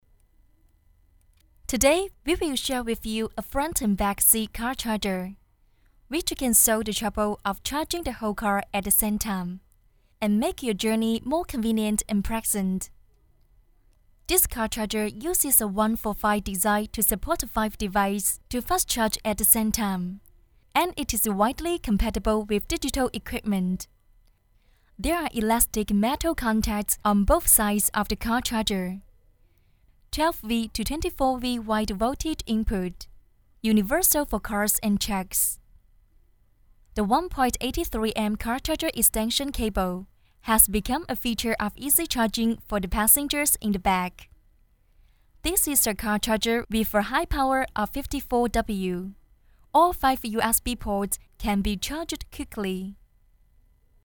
女43-温柔英文.mp3